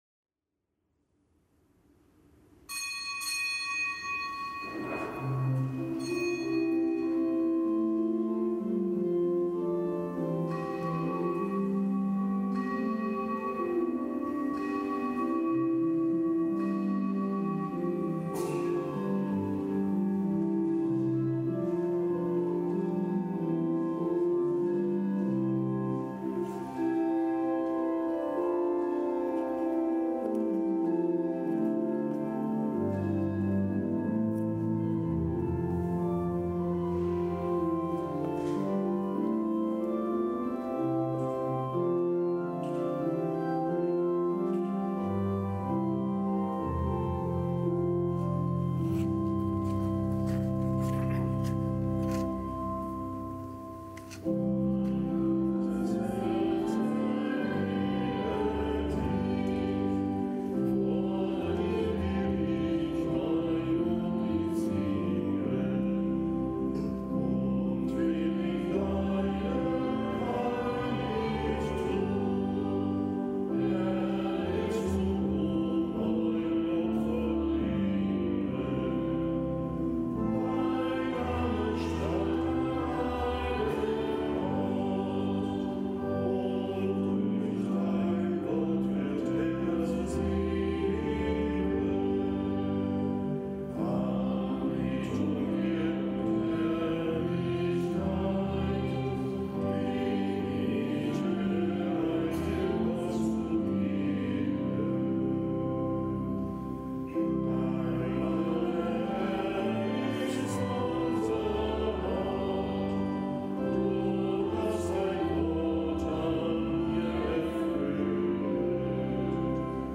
Kapitelsmesse aus dem Kölner Dom am Donnerstag der siebenundzwanzigsten Woche im Jahreskreis.